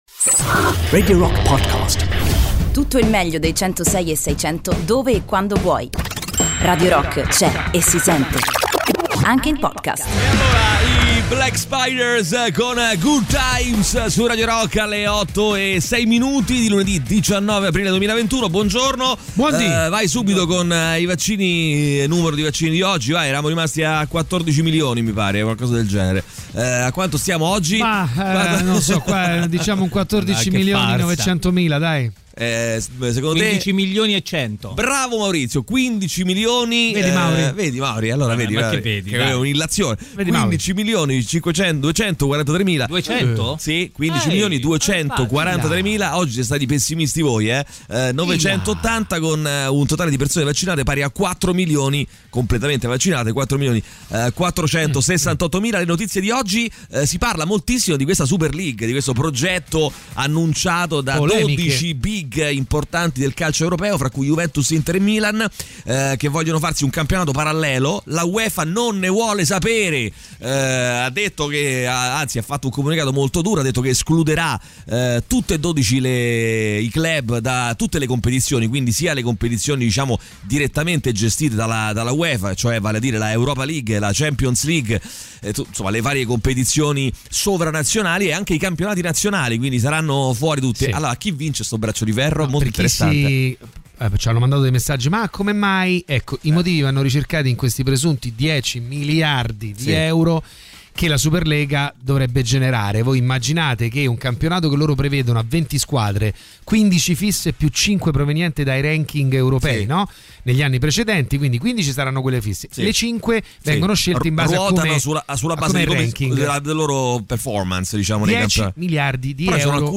in diretta dalle 08.00 alle 10.00 dal Lunedì al Venerdì sui 106.6 di Radio Rock. In studio